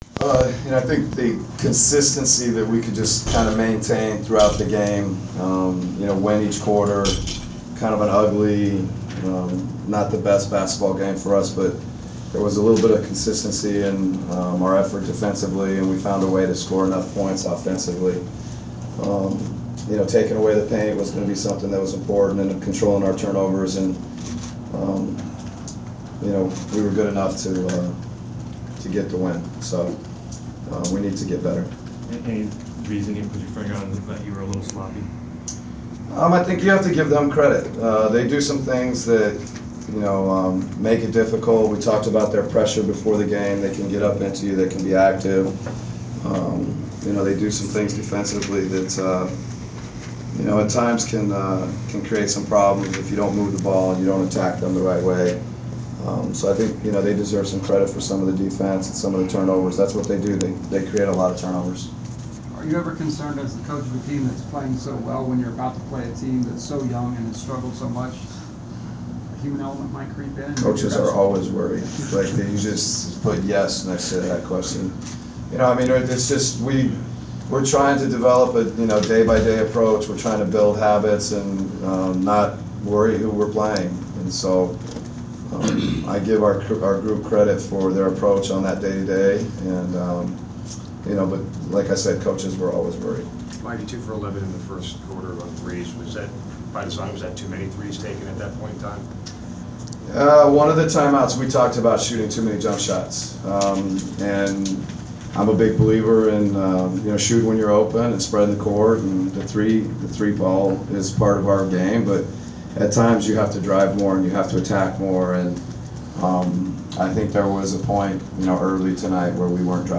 Inside the Inquirer: Postgame interview with Atlanta Hawks’ coach Mike Budenholzer (12/10/14)
We attended the postgame press conference of Atlanta Hawks’ head coach Mike Budenholzer following the team’s 95-79 home win over the Philadelphia 76ers on Dec. 10. Topics included overall thoughts on the game, offensive effort and game plan as well as efforts of Mike Muscala.